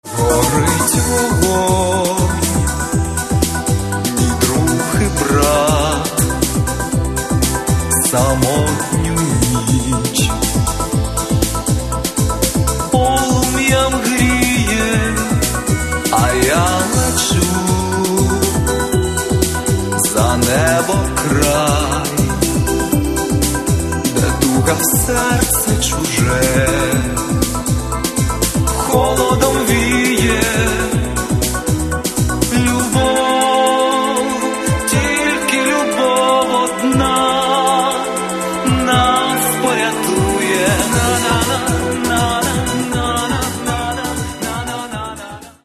Каталог -> Поп (Легкая) -> Сборники
синт-поп и неоромантика